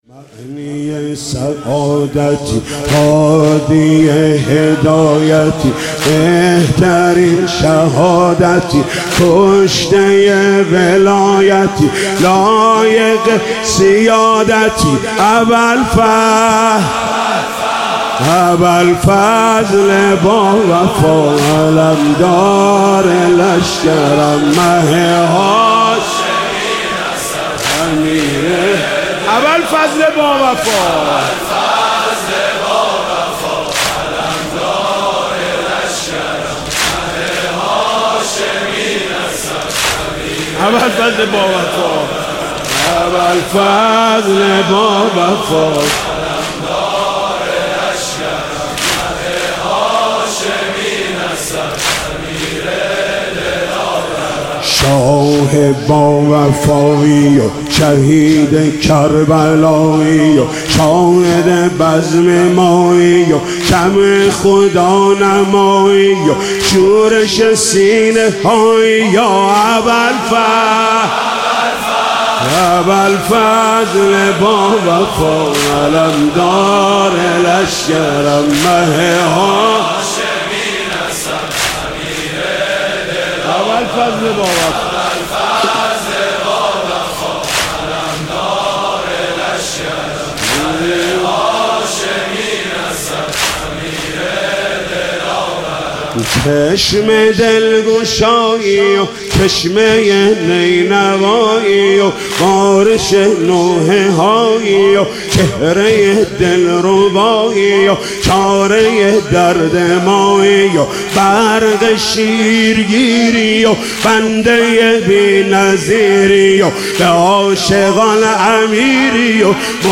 مراسم شب عاشورا محرم 97 اضافه شد.